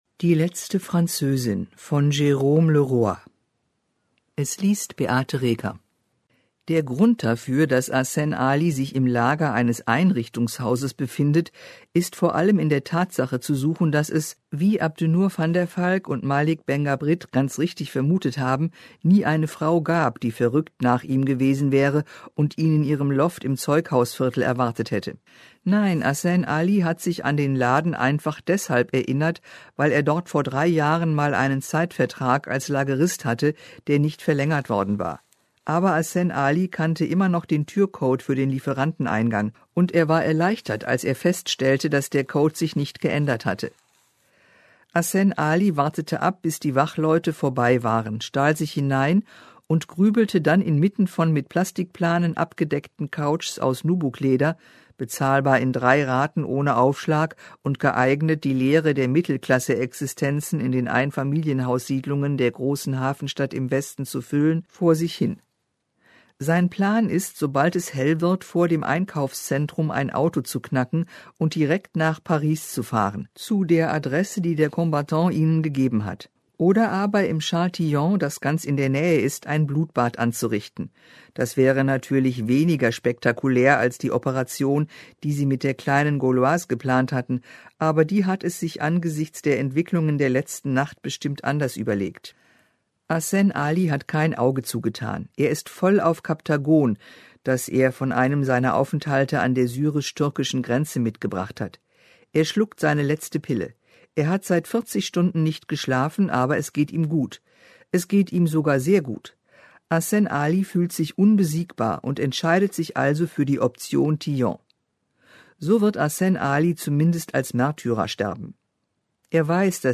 liest diesen vielschichtigen Roman für Sie: